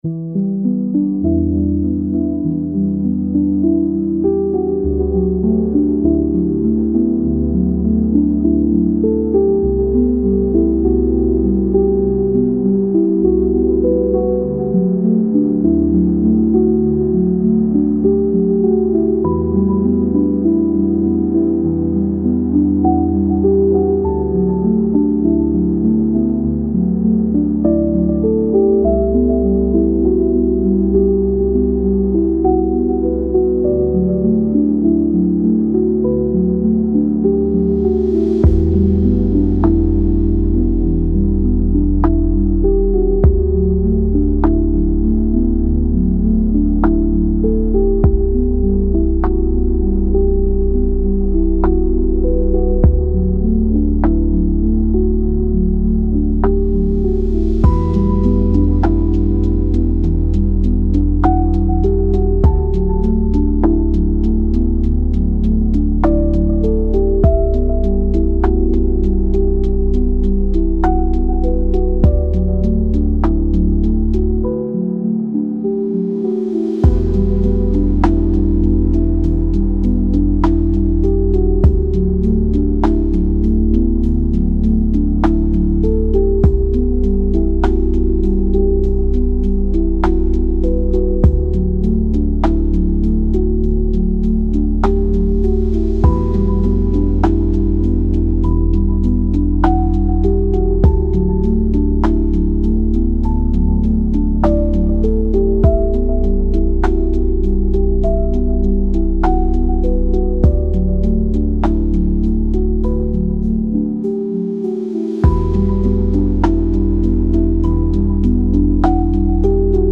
Sourire sans y penser · lo-fi jazzy pour travailler léger